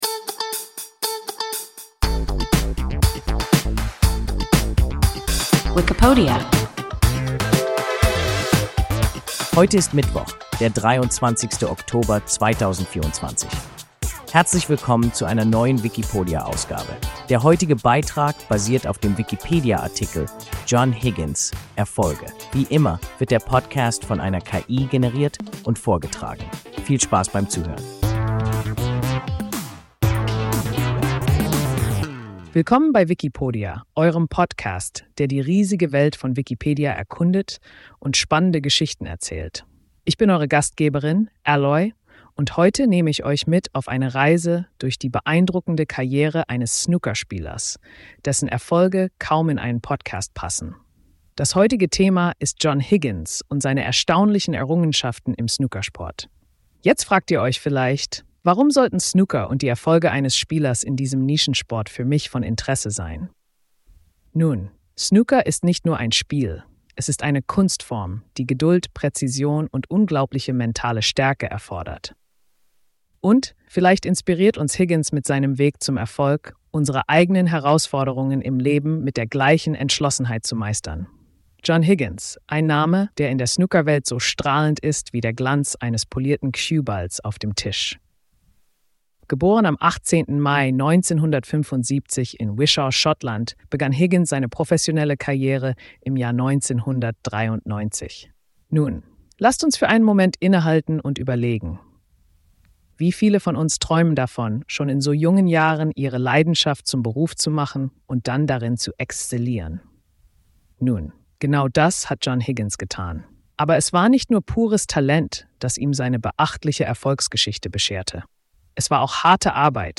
John Higgins/Erfolge – WIKIPODIA – ein KI Podcast